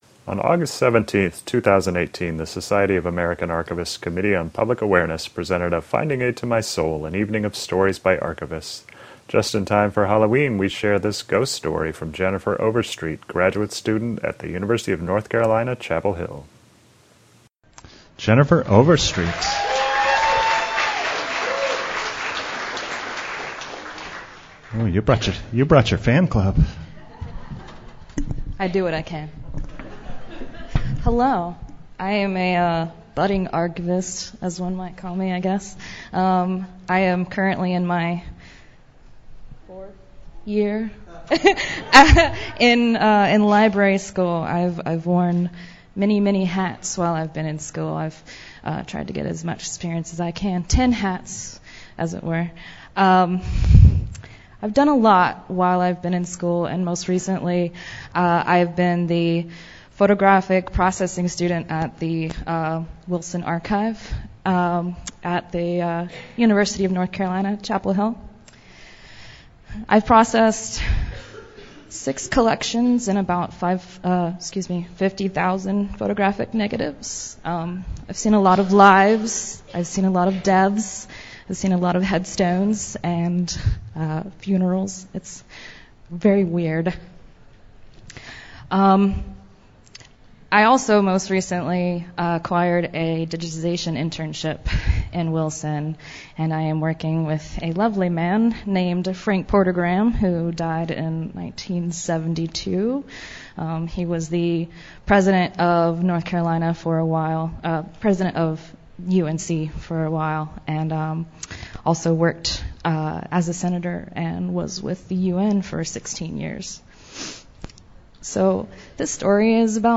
The event was a smashing success, with twelve archivists sharing stories to a packed room that were sad, funny, profound, and even a little scary at times.